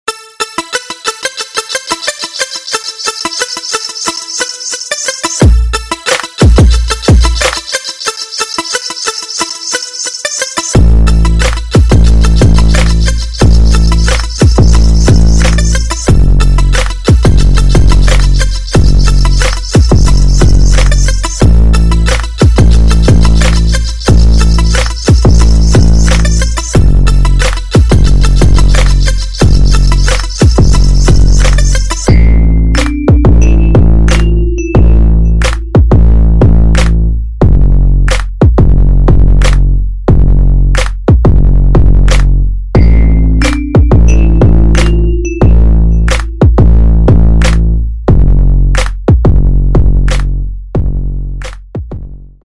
Стерео звук - Проверка звука по каналам, наушники, колонки
• Категория: Тест акустики
• Качество: Высокое